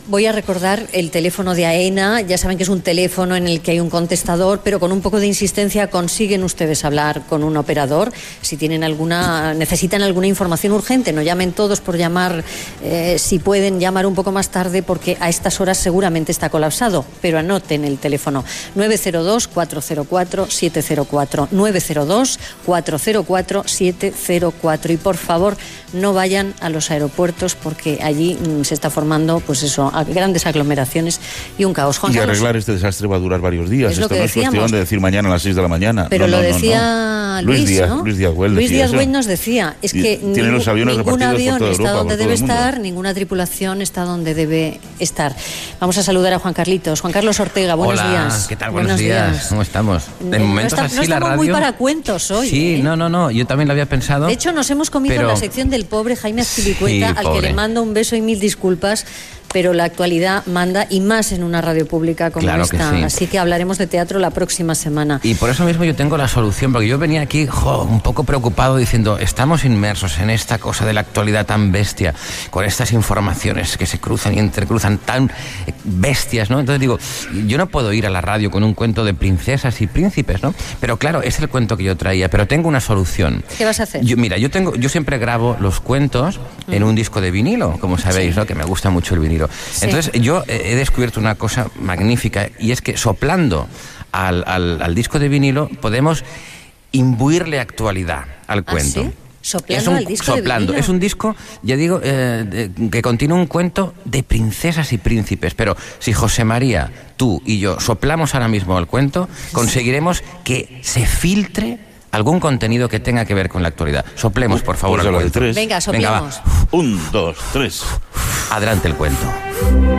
Espai dedicat als viatges (els preus dels taxis al món i altres temes) Gènere radiofònic Entreteniment